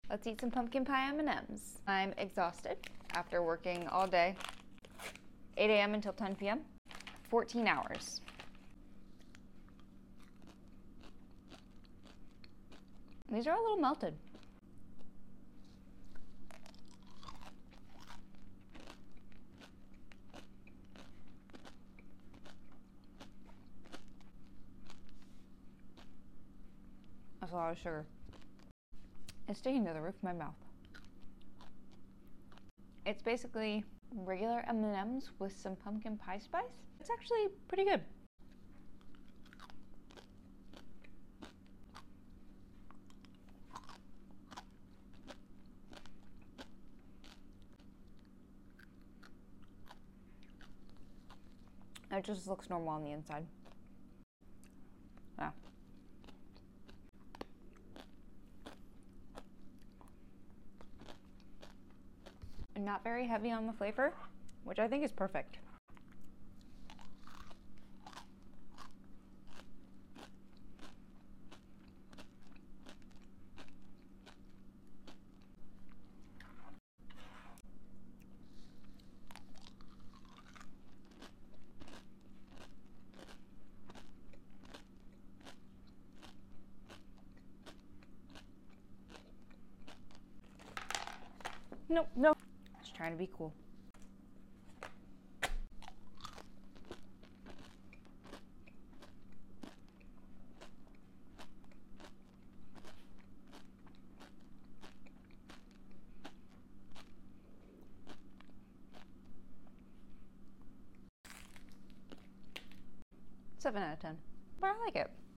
Pumplin Pie M&Ms Mukbang 🥧 Sound Effects Free Download